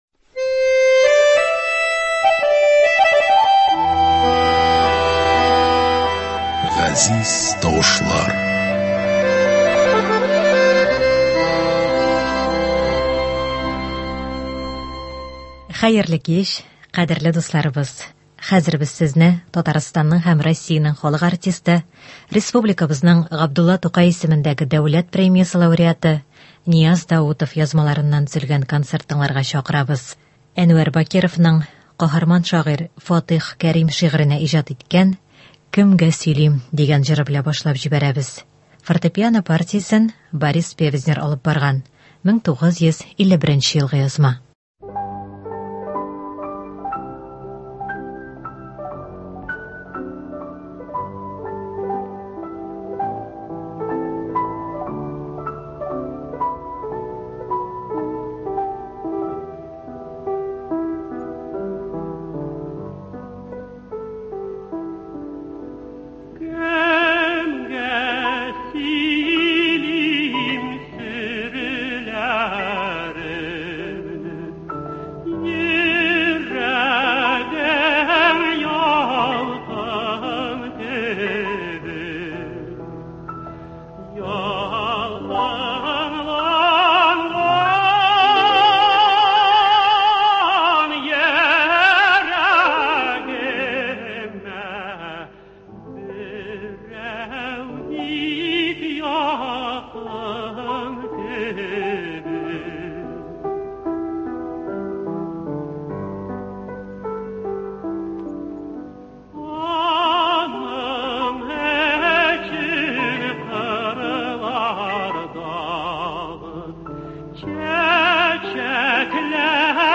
Концерт (22.09.22)